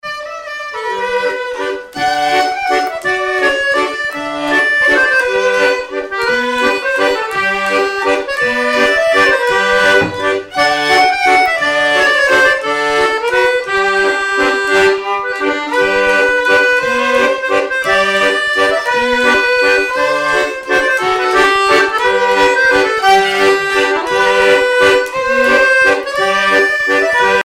Miquelon-Langlade
danse : valse
violon
Pièce musicale inédite